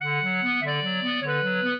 clarinet
minuet4-8.wav